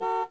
Buzina.mp3